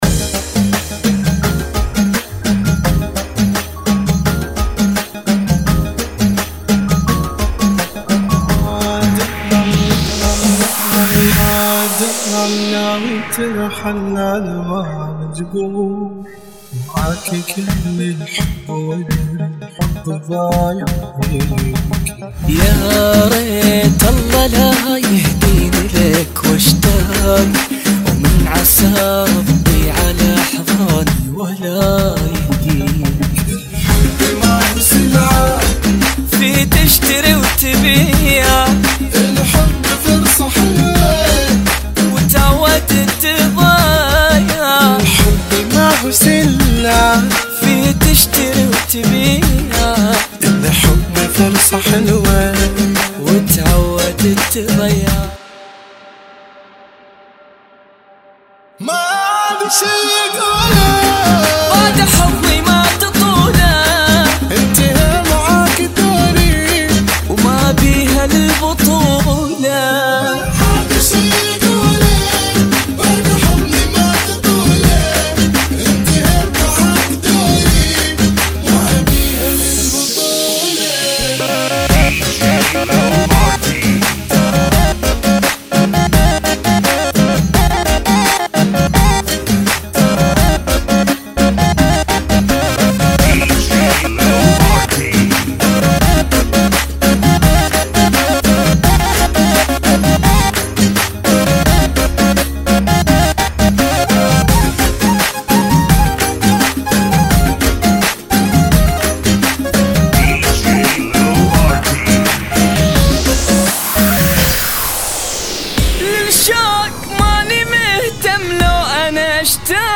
Bbm 85